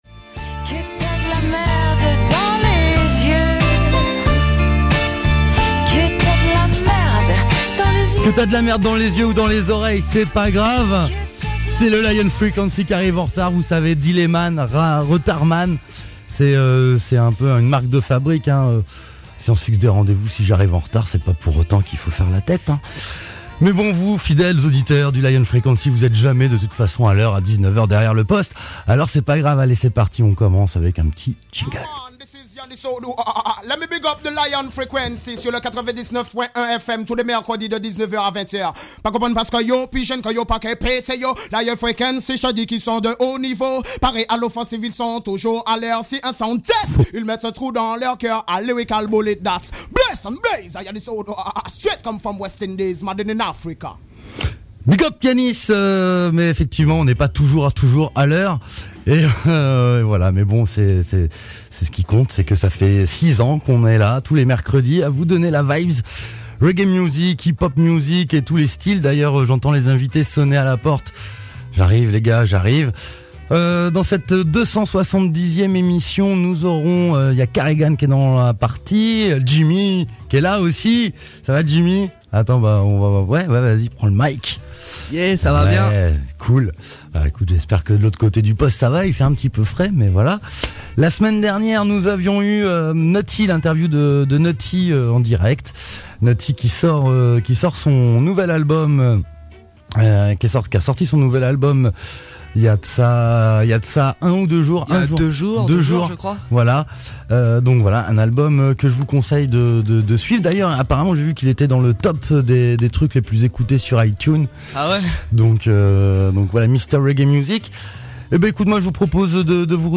* Lion FreeCaency Radio Show *